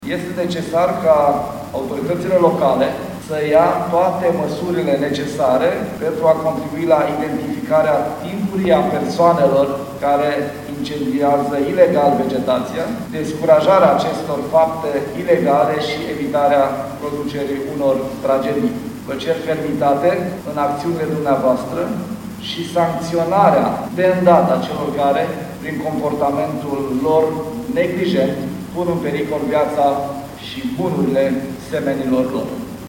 În primele 7 luni ale anului au fost mai puține incendii de pădure față de anul trecut, spune ministrul de interne, Lucian Bode. El a cerut autorităților locale să nu slăbeasca vigilența și să îi sancționeze pe cei care dau foc intenționat vegetației uscate: